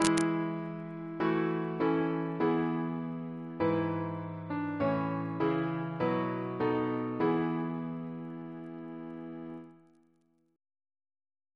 Single chant in E Composer: James Turle (1802-1882), Organist of Westminster Abbey Reference psalters: ACB: 68; CWP: 30; OCB: 112; PP/SNCB: 66; RSCM: 213